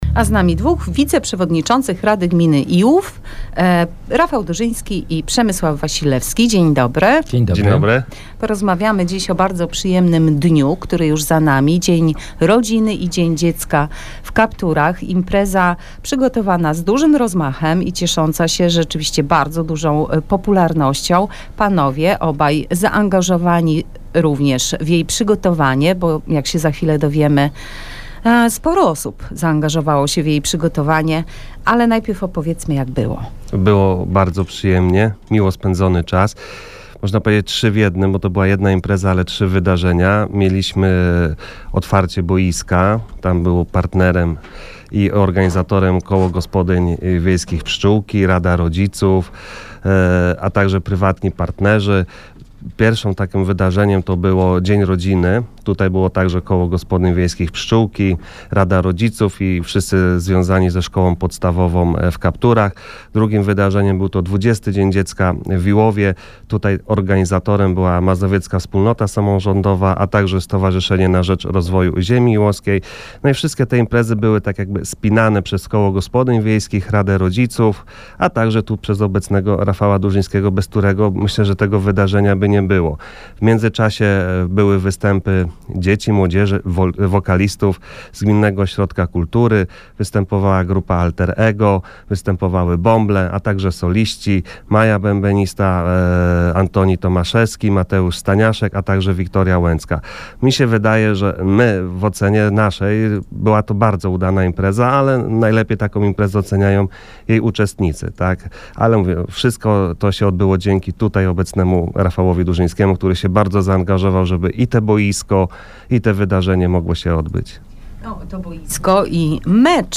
Wywiad z Wiceprzewodniczącymi Rady Gminy Przemysławem Wasilewskim i Rafałem Durzyńskim - Najnowsze - Gmina Iłów